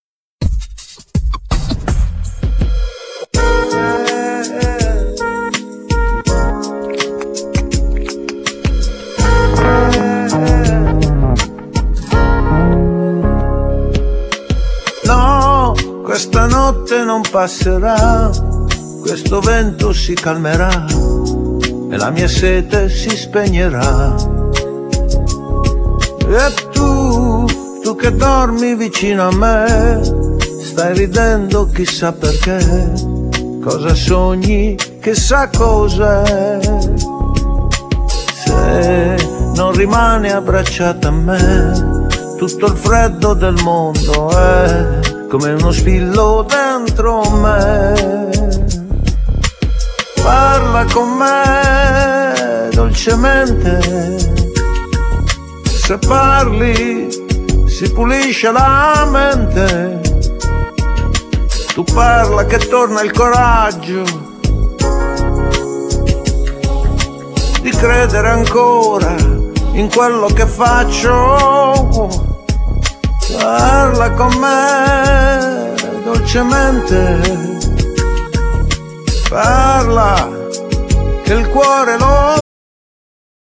Genere : Pop / funky
voce inconfondibile e sempre attuale.